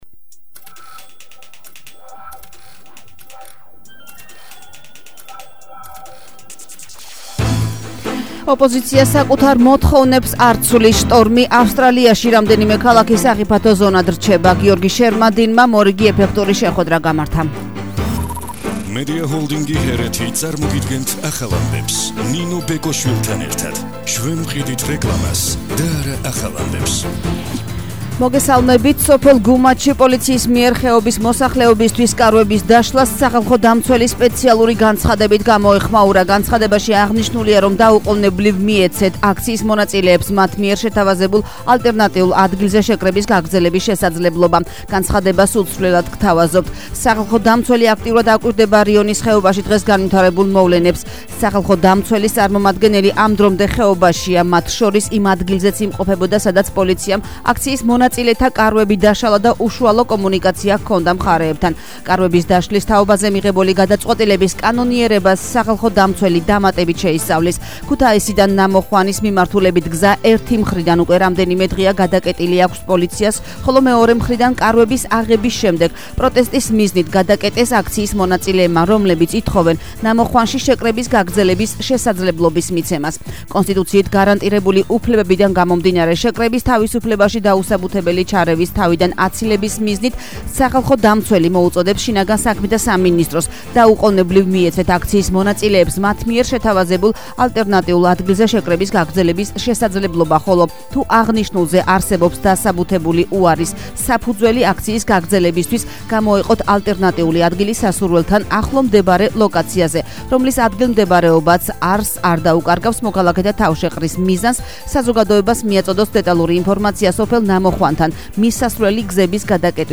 ახალი ამბები 11:00 საათზე –12/04/21